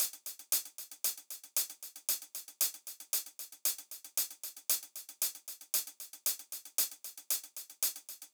01 Hihat.wav